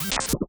SCIMisc_Reload Alien Tech_02.wav